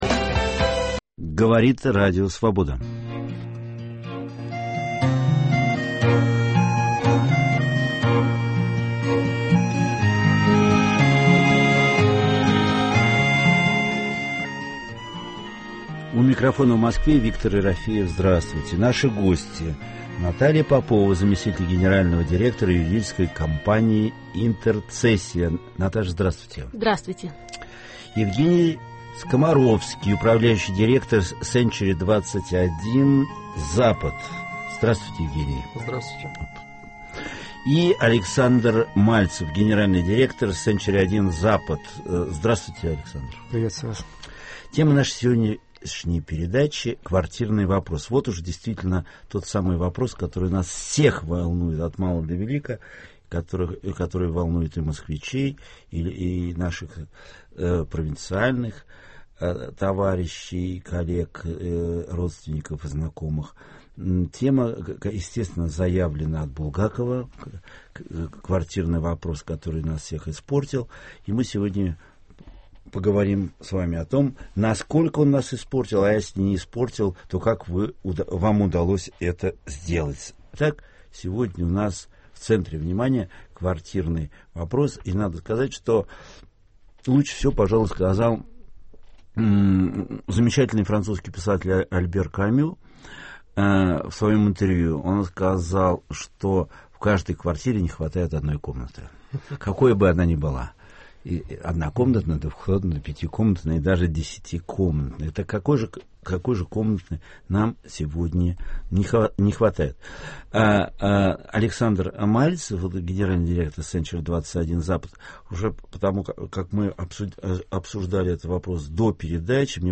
Живой опыт самопознания в прямом эфире с участием слушателей, который ведет писатель Виктор Ерофеев. Это попытка определить наши главные ценности, понять, кто мы такие, о чем мы спорим, как ищем и находим самих себя.